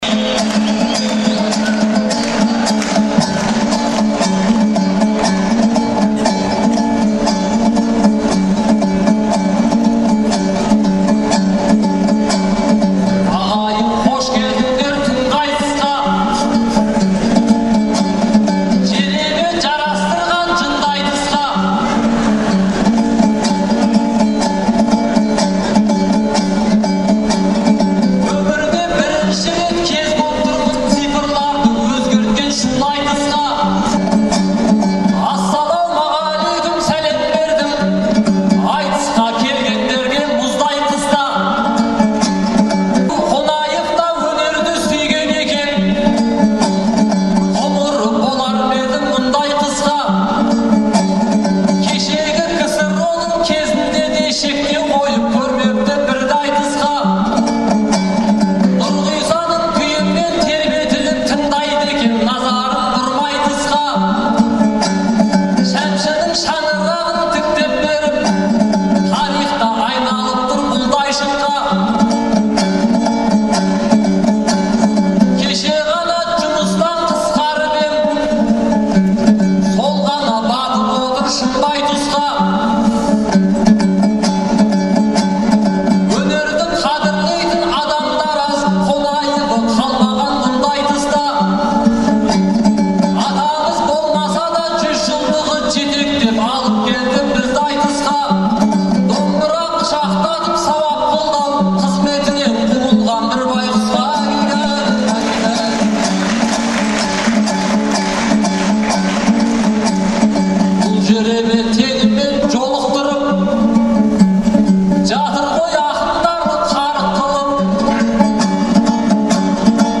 Алматыдағы Балуан Шолақ атындағы спорт сарайында ақпанның 11-і мен 12-сі күні «Қонаевтай ер қайда» деген атпен айтыс өтті.